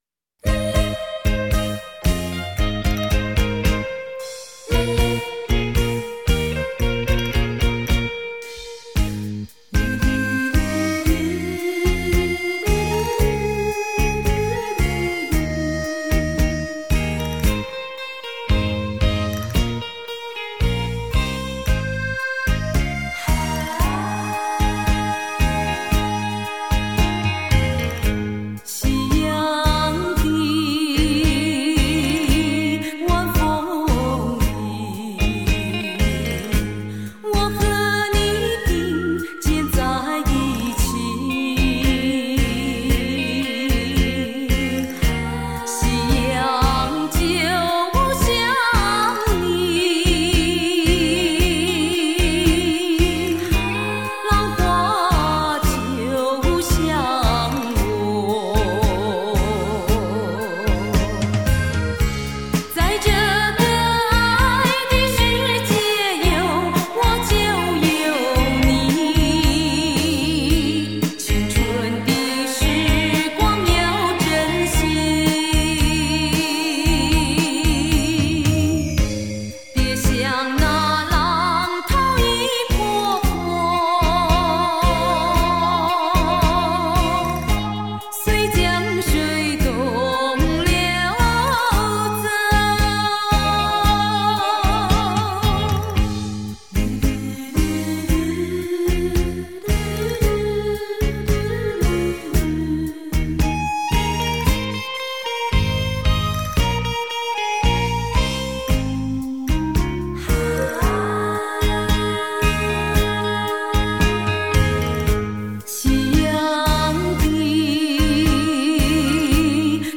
舞厅规格